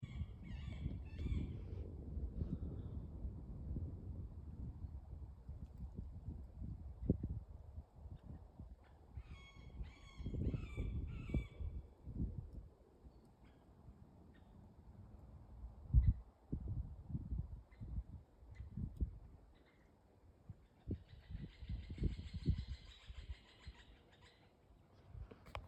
средний дятел, Leiopicus medius
СтатусПоёт